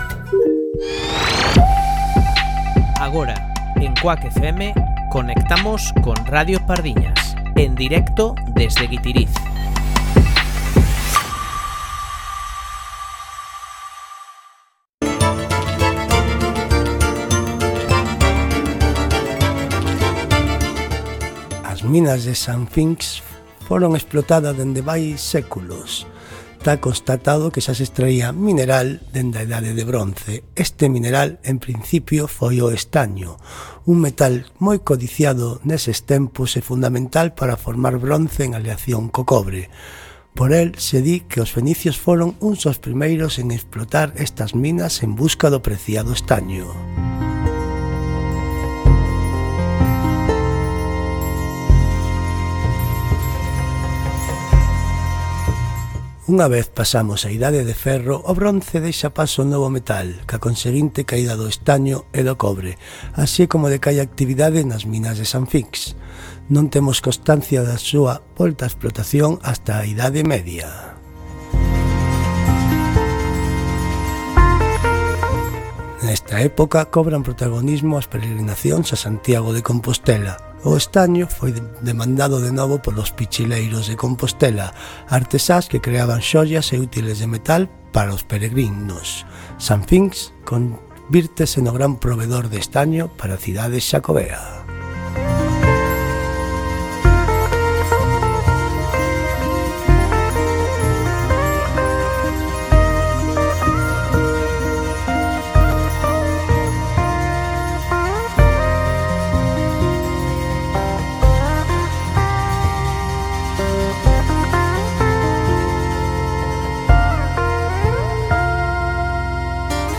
Conexión en directo con Radio Pardiñas, a emisión radiofónica desde o Festival de Pardiñas (Pardiñas, Guitiriz, Lugo).